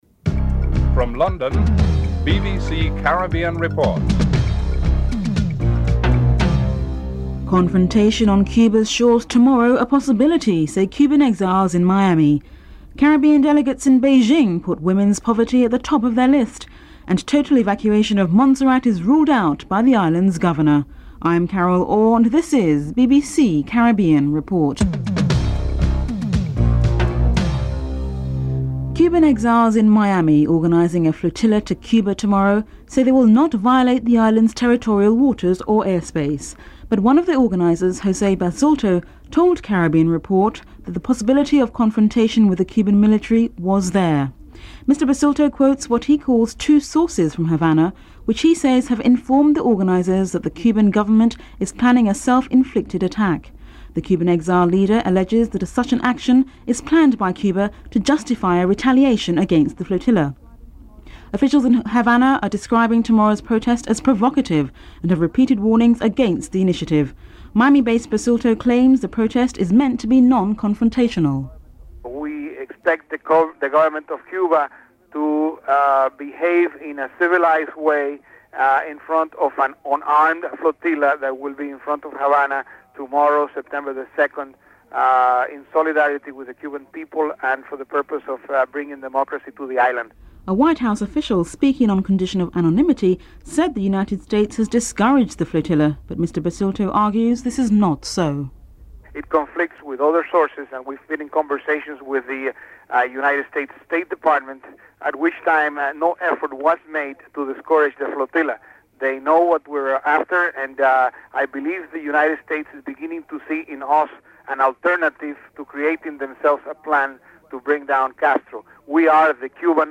The British Broadcasting Corporation